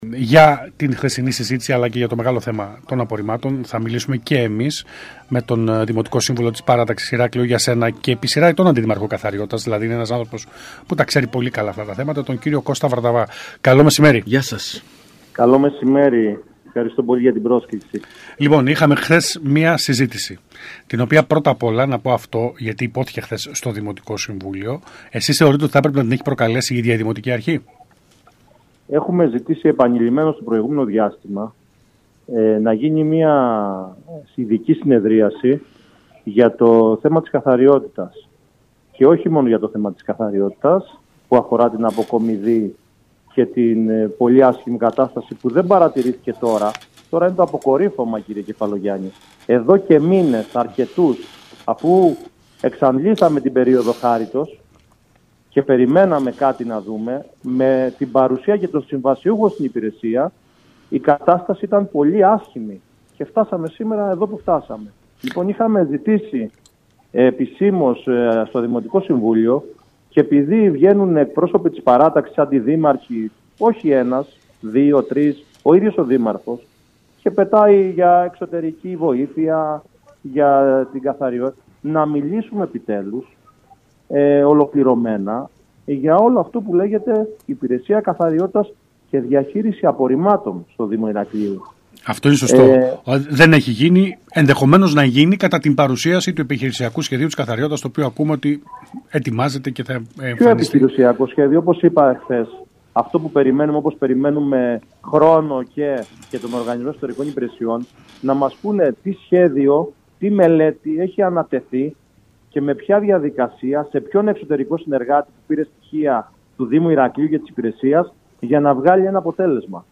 μίλησε το μεσημέρι της Τρίτης 6 Μαίου στον ΣΚΑΙ Κρήτης 92.1